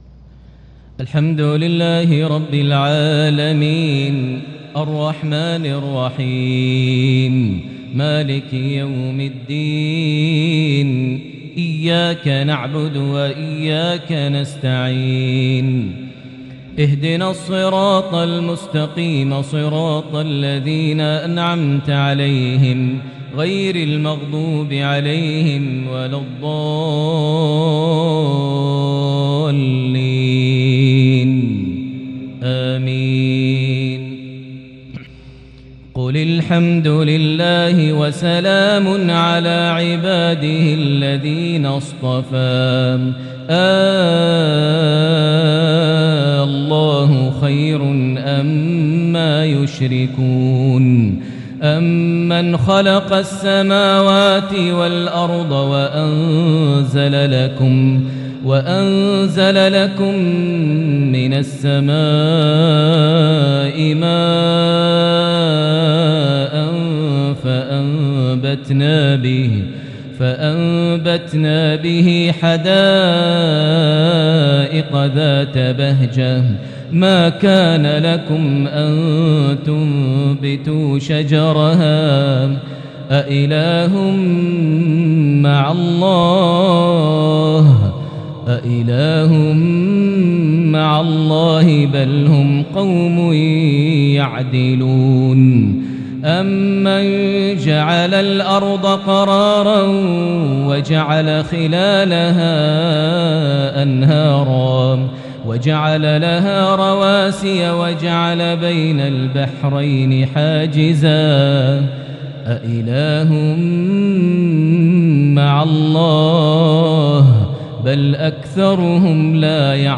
maghrib 1-1-2022 prayer from Surah An-Naml 59-66 > 1443 H > Prayers - Maher Almuaiqly Recitations